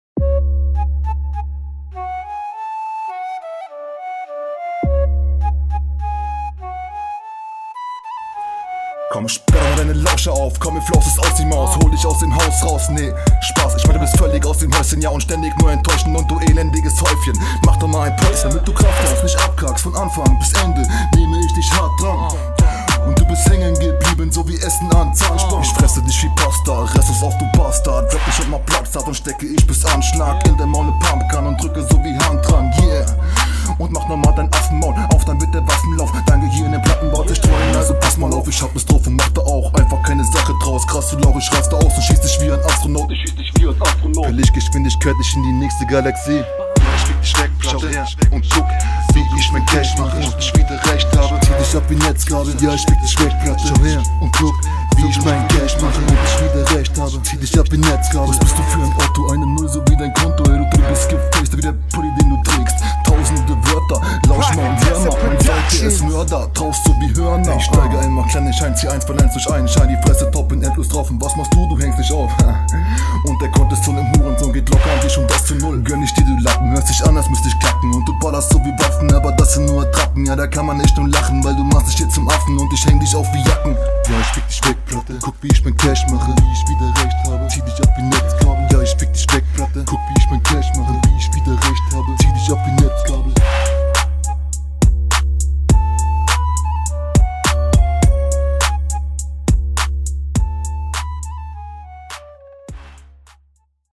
Du flowst gut auf den beat, viele gute punchlines sind dabei deine technik gefällt mir …
Die Abmische geht gut rein.